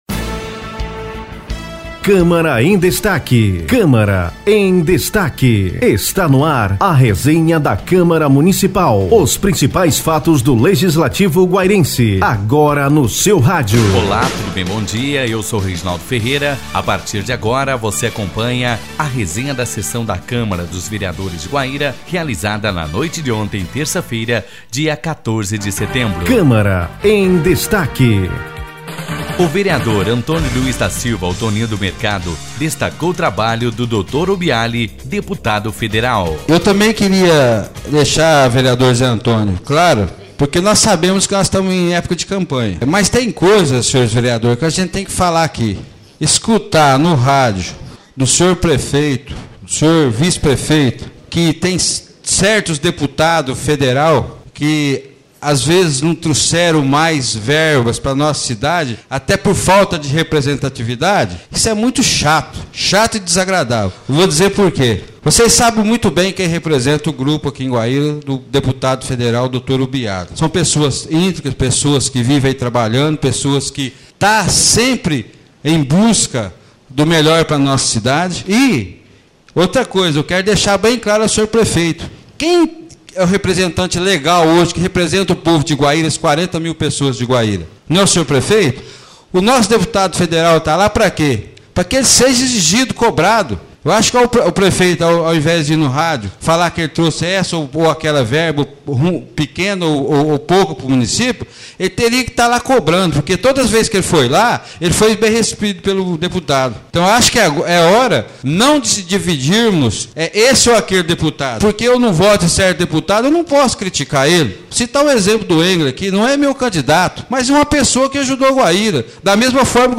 Ouça a resenha da 15ª sessão ordinária realizada no dia 14/09